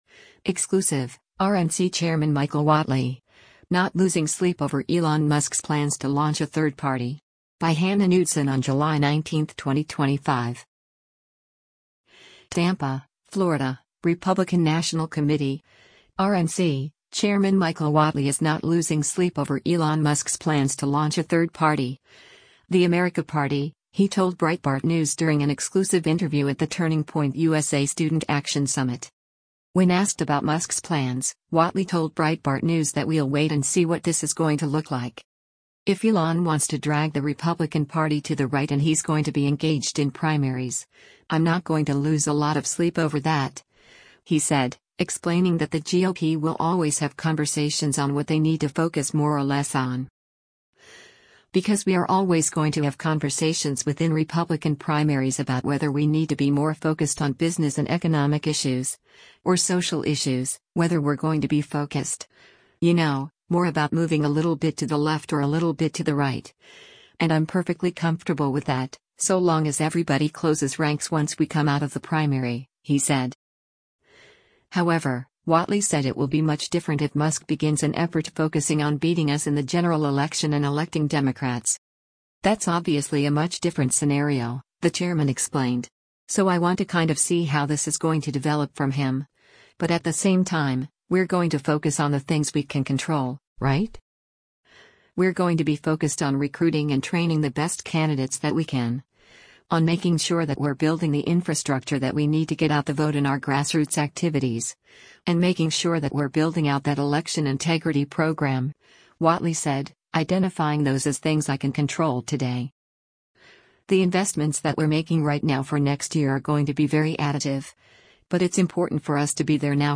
TAMPA, Florida — Republican National Committee (RNC) Chairman Michael Whatley is not losing sleep over Elon Musk’s plans to launch a third party — the America Party — he told Breitbart News during an exclusive interview at the Turning Point USA Student Action Summit.